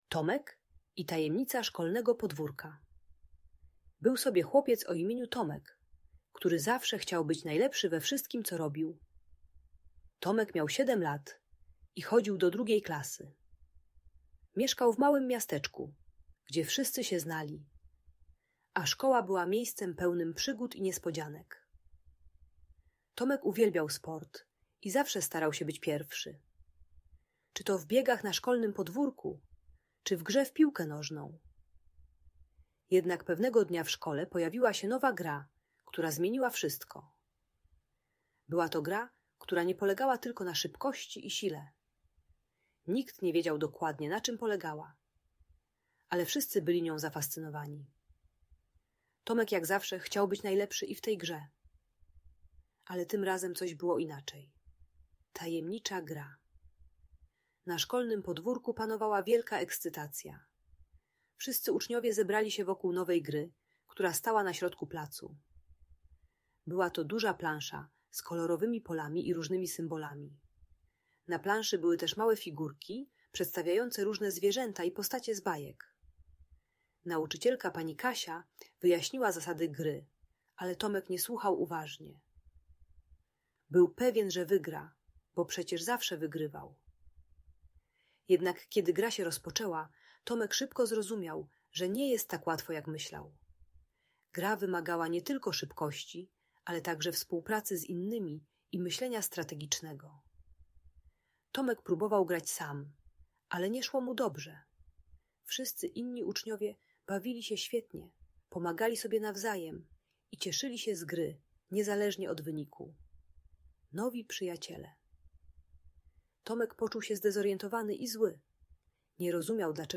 Tomek i Tajemnica Szkolnego Podwórka - Audiobajka